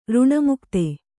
♪ řṇamukte